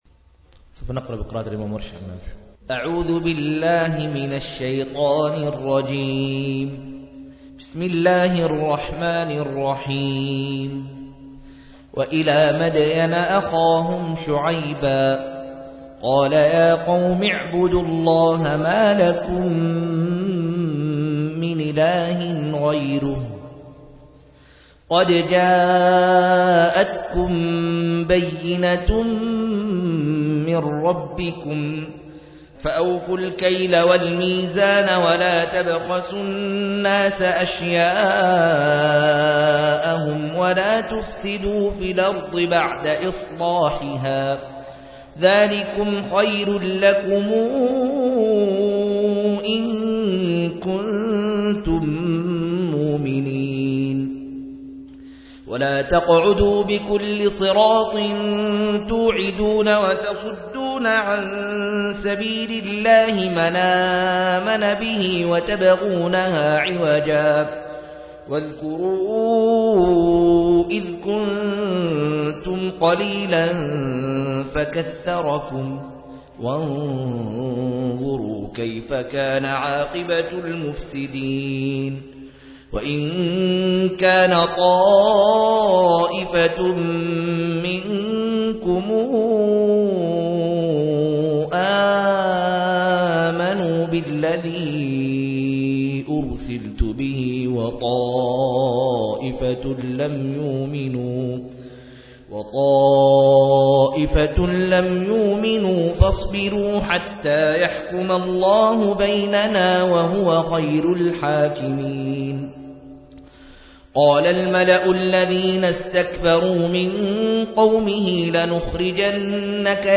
153- عمدة التفسير عن الحافظ ابن كثير رحمه الله للعلامة أحمد شاكر رحمه الله – قراءة وتعليق –